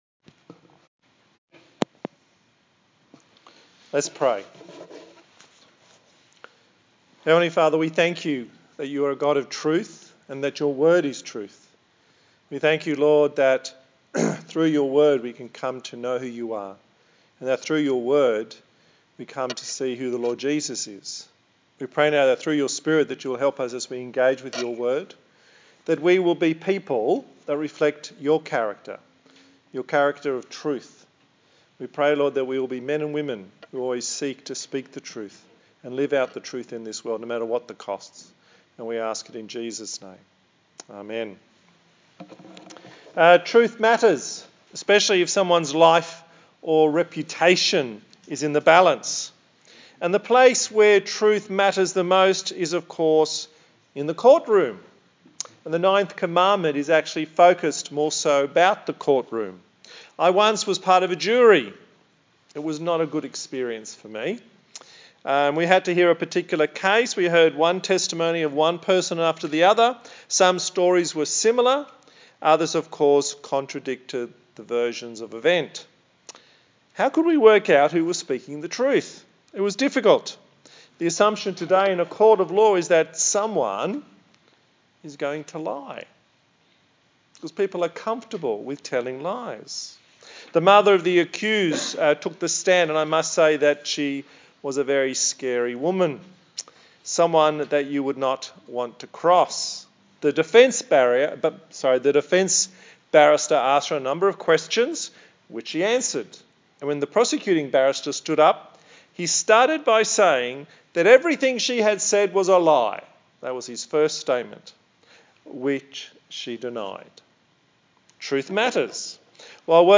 A sermon in the series on The Ten Commandments
Service Type: TPC@5